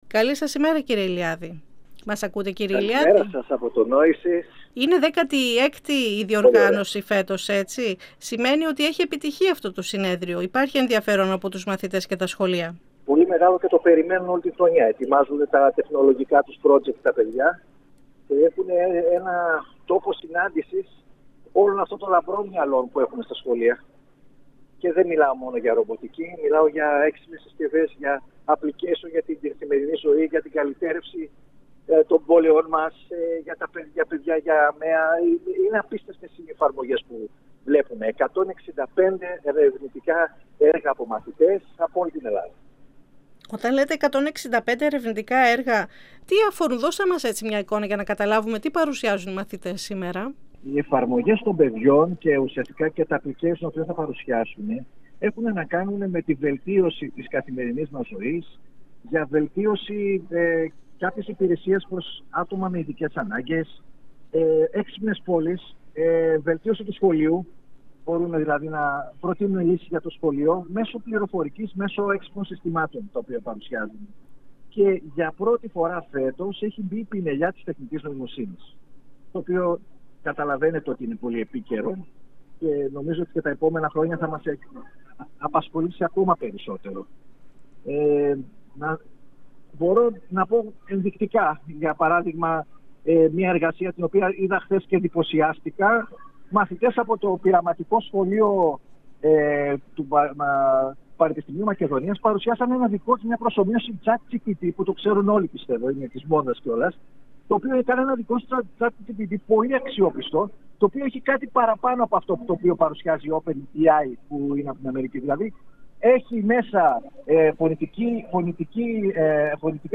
μιλώντας στην εκπομπή «Αίθουσα Σύνταξης» του 102FM της ΕΡΤ3.